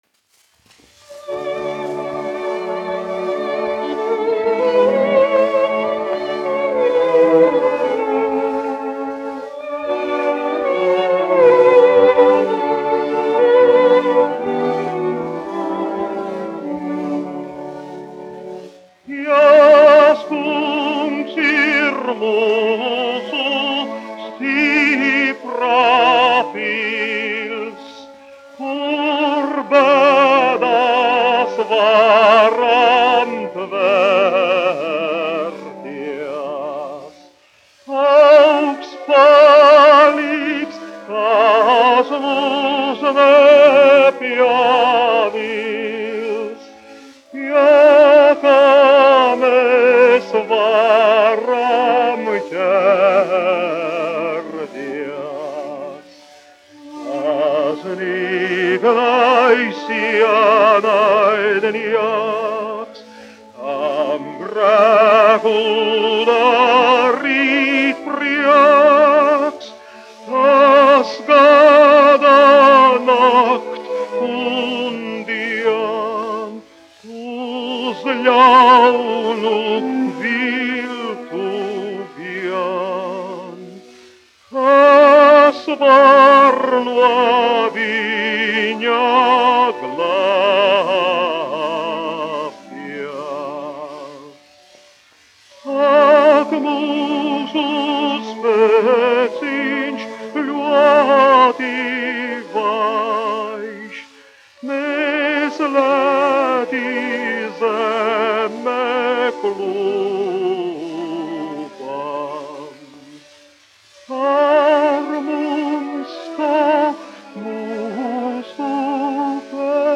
Mariss Vētra, 1901-1965, dziedātājs
1 skpl. : analogs, 78 apgr/min, mono ; 25 cm
Korāļi
Garīgās dziesmas
Latvijas vēsturiskie šellaka skaņuplašu ieraksti (Kolekcija)